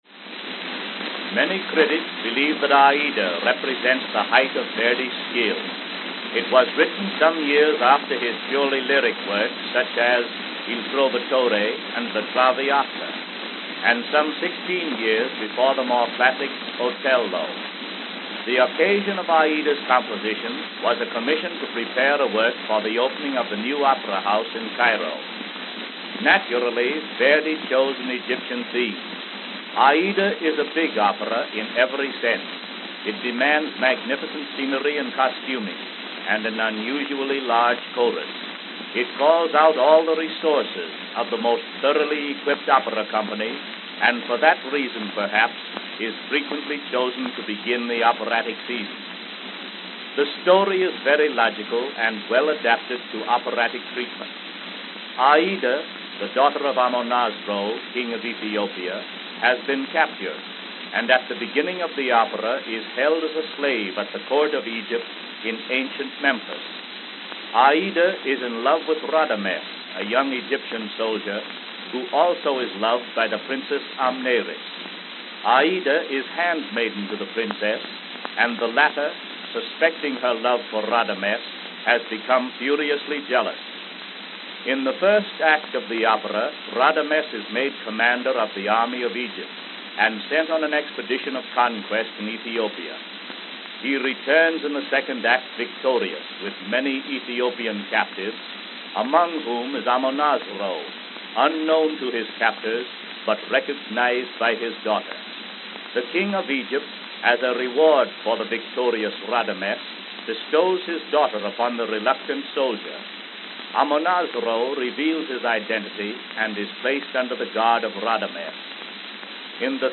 The L-Side contained a spoken description of the song and artist.
New York, New York New York, New York